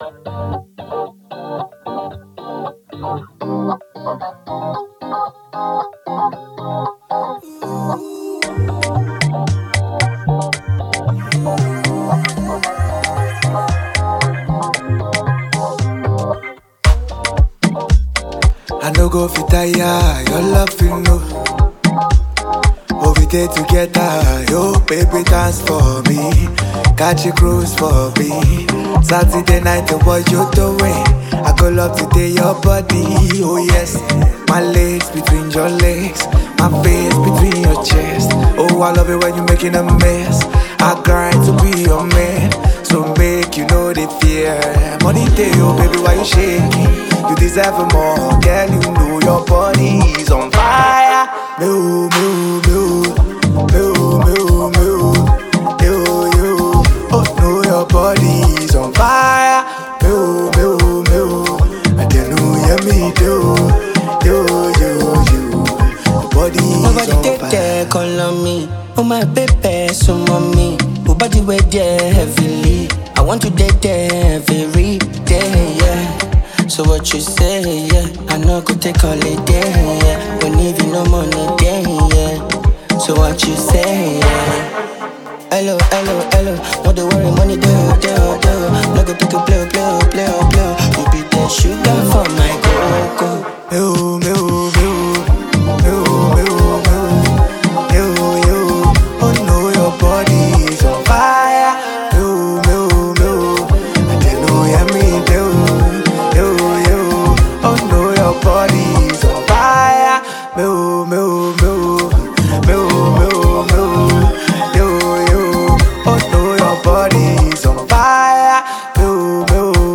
radiates summer energy and laidback groove